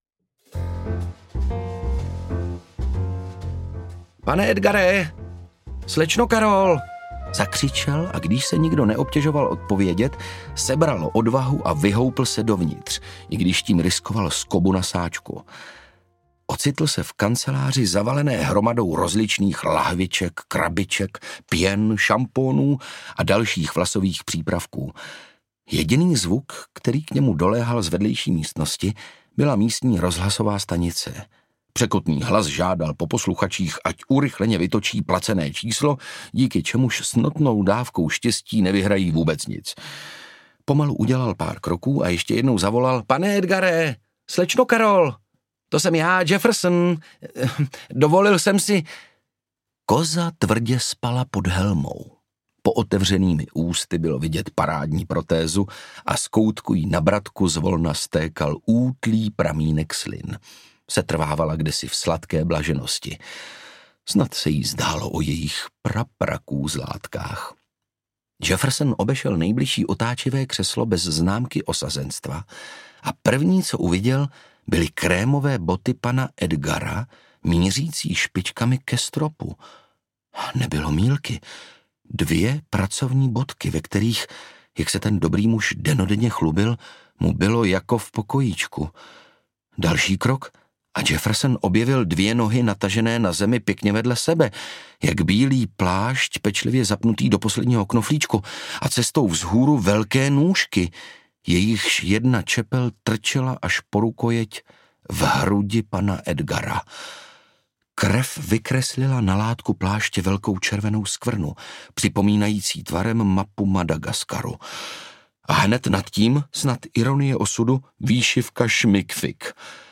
Jefferson audiokniha
Ukázka z knihy
• InterpretOndřej Brousek